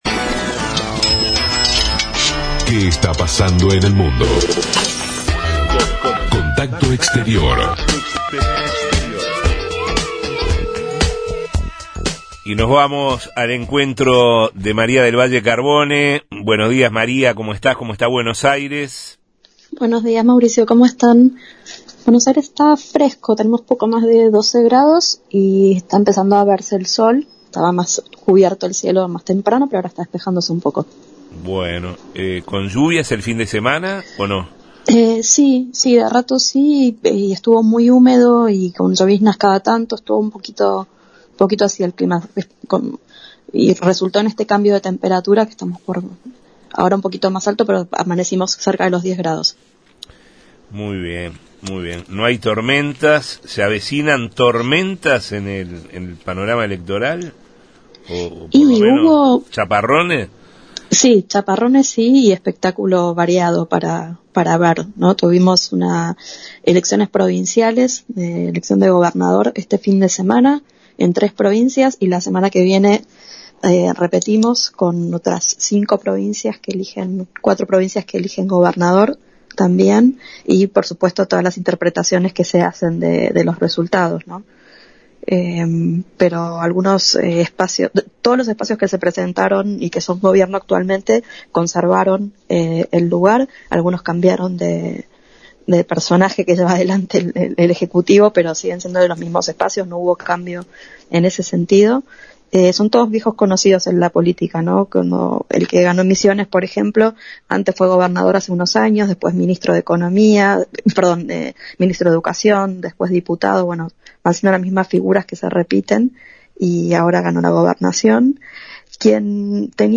Corresponsal